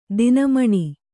♪ dina maṇi